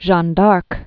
(zhän därk)